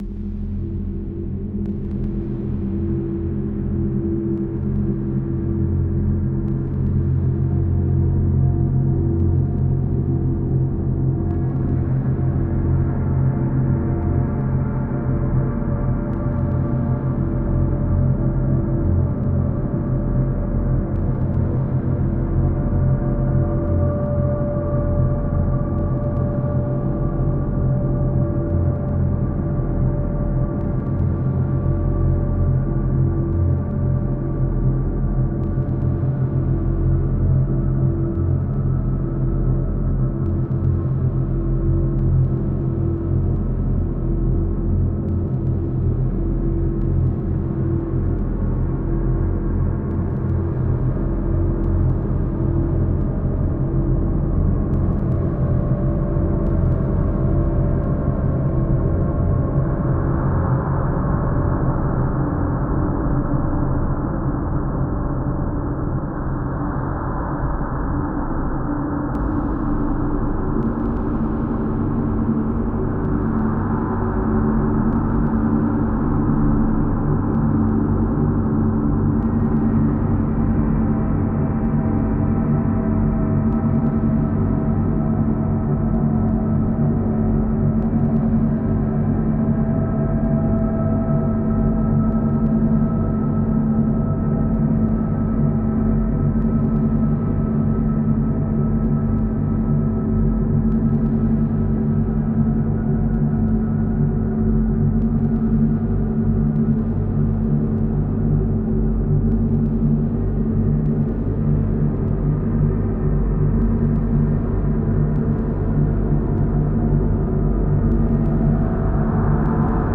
Pièce sonore